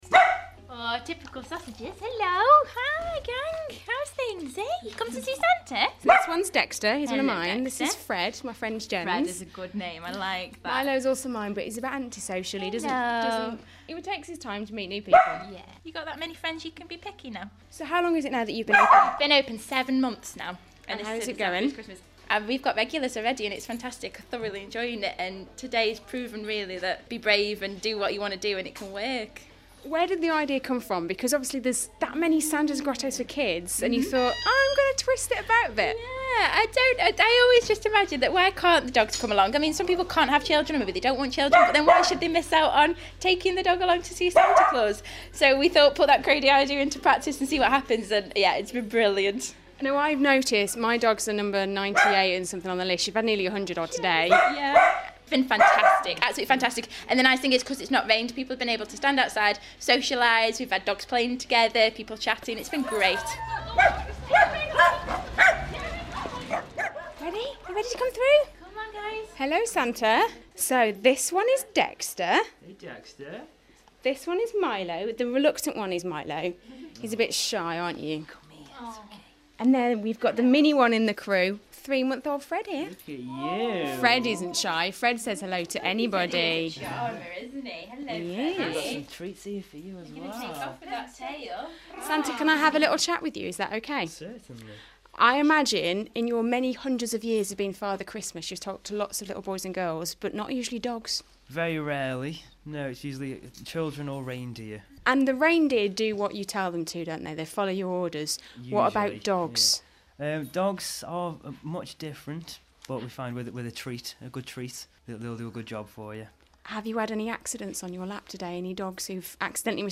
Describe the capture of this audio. (Broadcast on BBC Radio Stoke, December 2016)